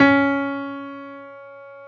55p-pno19-C#3.wav